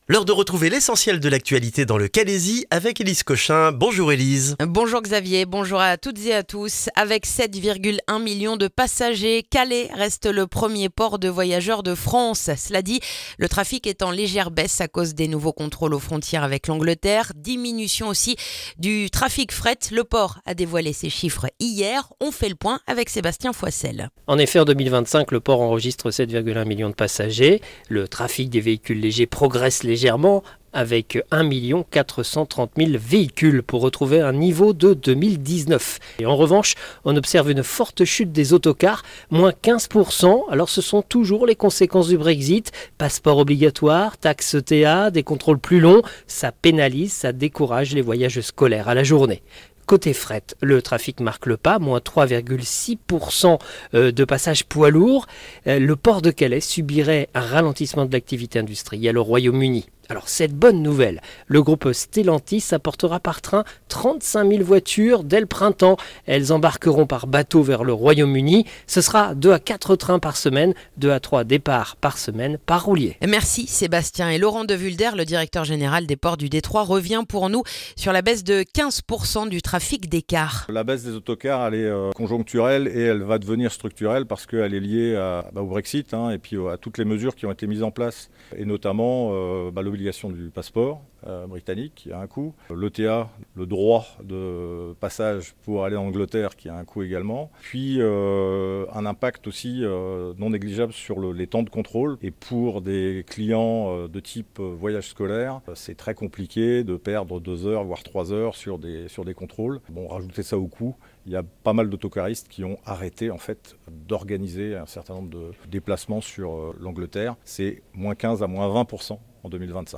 Le journal du jeudi 22 janvier dans le calaisis